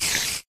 Sound / Minecraft / mob / spider2